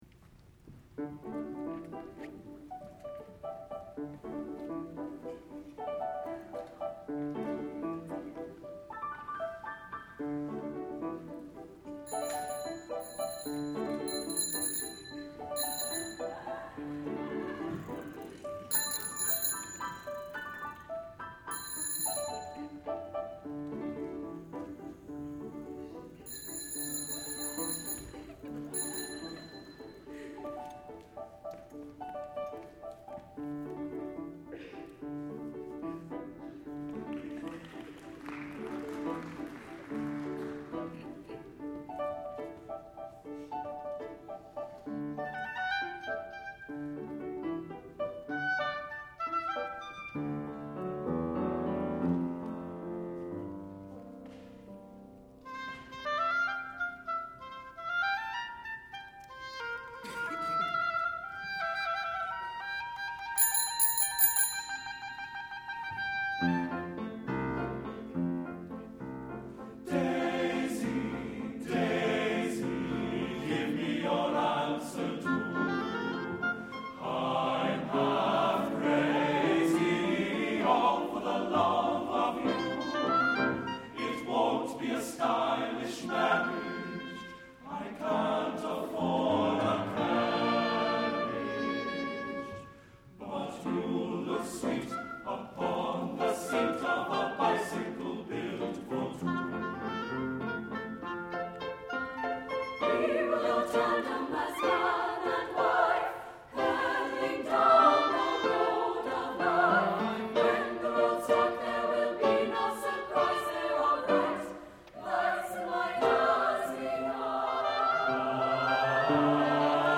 for SATB Chorus, Oboe, and Piano (2002)